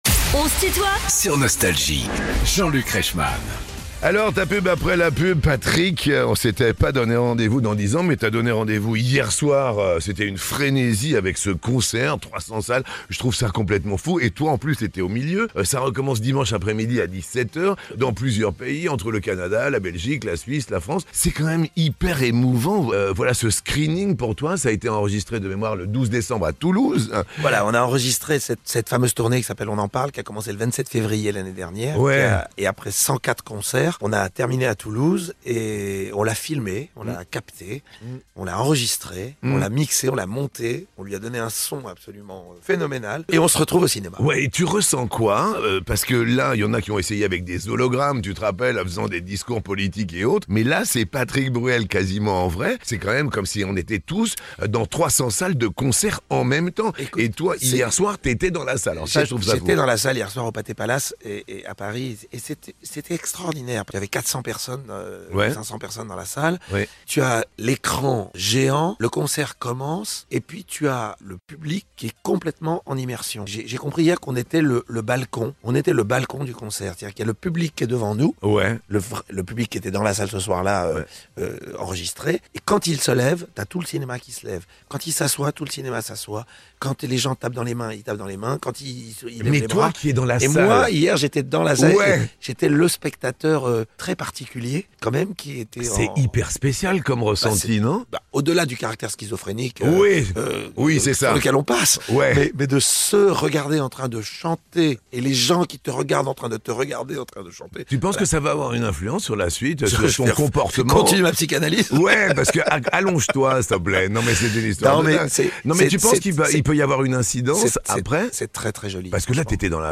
Les plus grands artistes sont en interview sur Nostalgie.